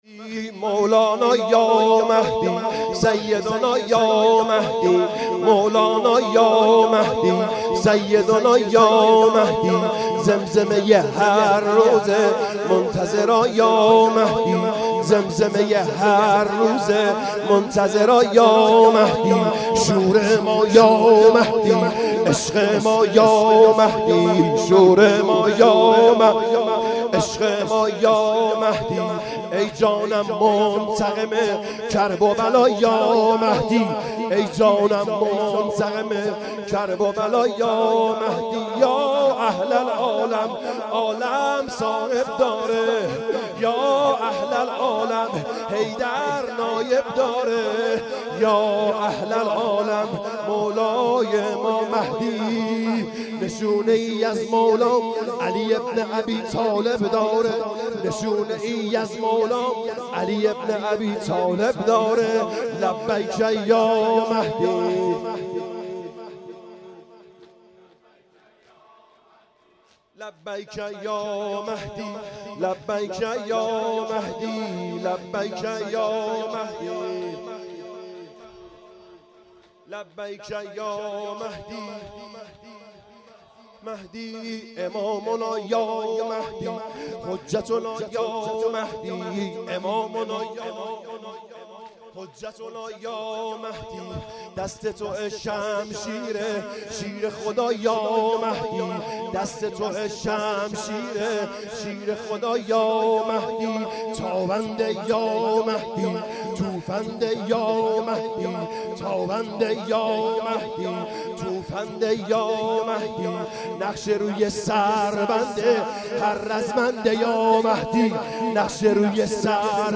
شب هفتم محرم